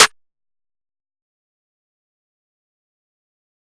Tomahawk Snare
Tomahawk-Snare.wav